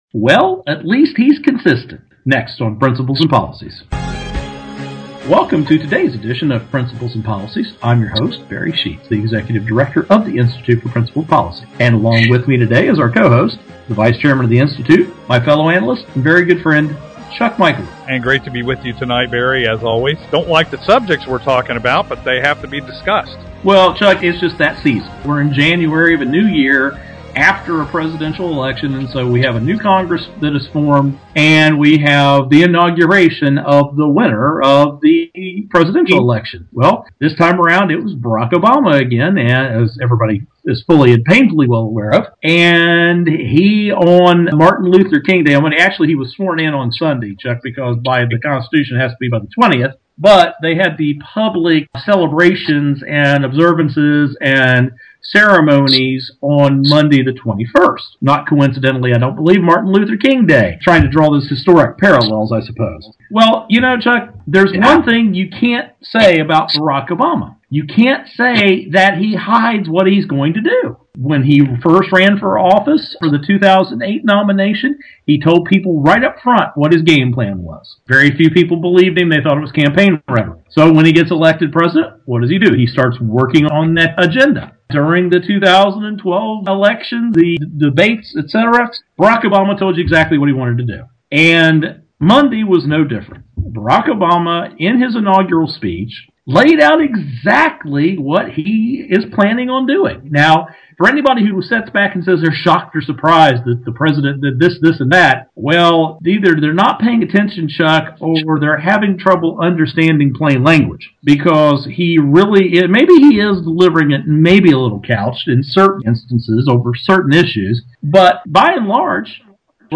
Our Principles and Policies radio show for Thursday January 24, 2013.